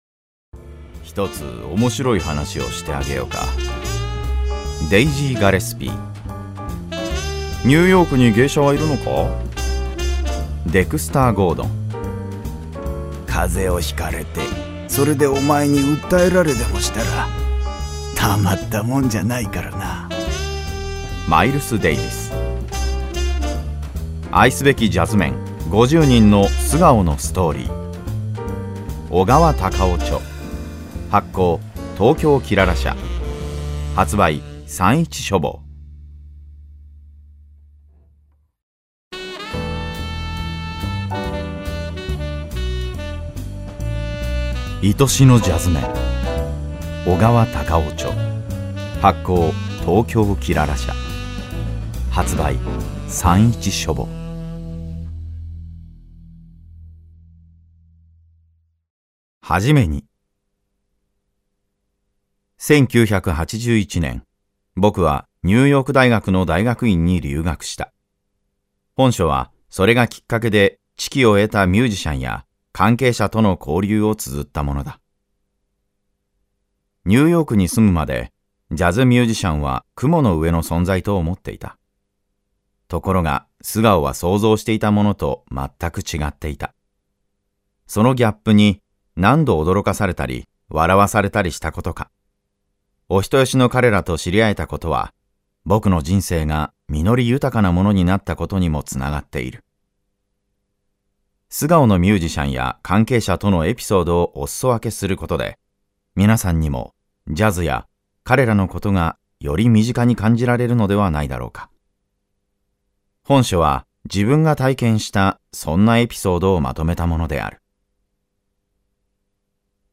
[オーディオブック] 愛しのジャズメン